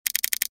Звуки скроллинга
Звук прокрутки интерфейса